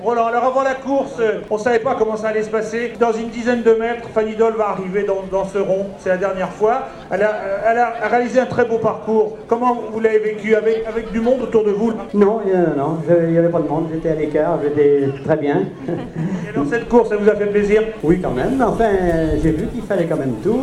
Les liens Audio, sont les enregistrement que j'ai éffectués le 31/12/2003 sur l'hippodrome du Bouscat à Bordeaux lors de la dernière course de FAN IDOLE.
(un peu court le micro a coupé)